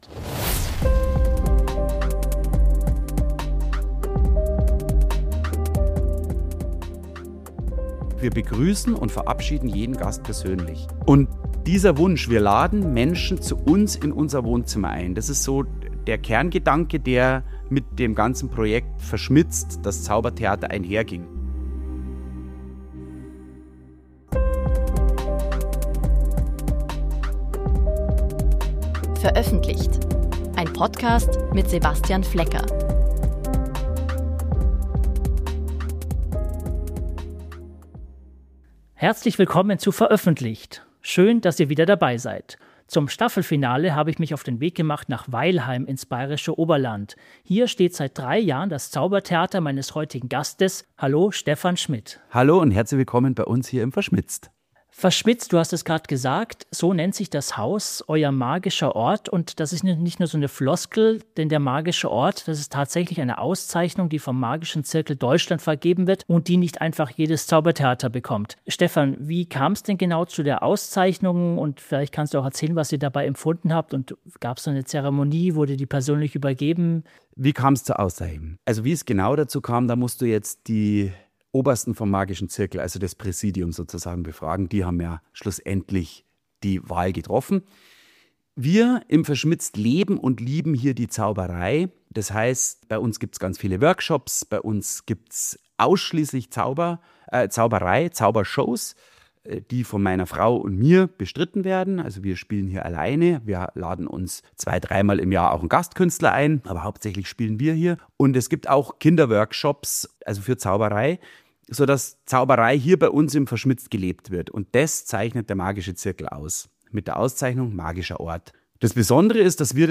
Ein Gespräch über Kreativität auf der Bühne, das Zaubern auf hoher See und wann aus Handwerk Magie entsteht.